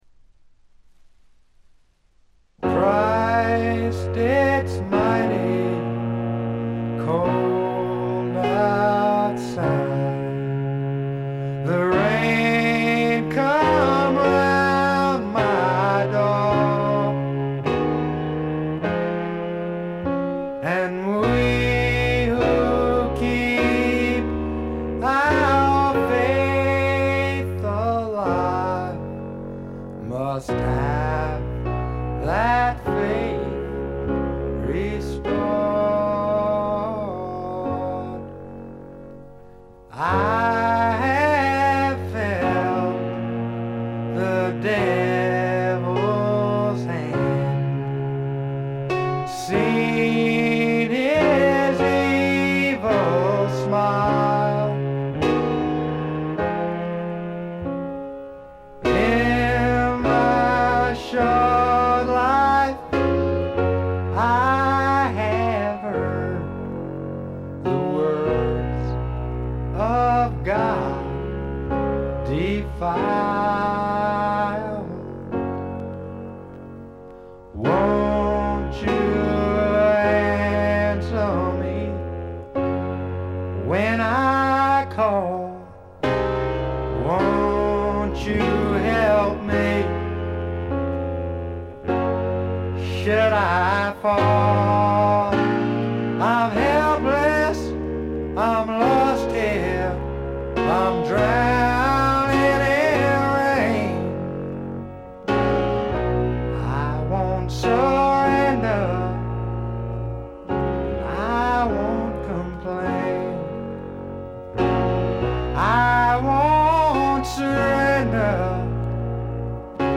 ほとんどノイズ感無し。
素晴らしい楽曲と素朴なヴォーカル。フォーキーな曲から軽いスワンプ風味を漂わせる曲までよく練られたアレンジもよいです。
試聴曲は現品からの取り込み音源です。
Vocals, Piano, Harmonica, Acoustic Guitar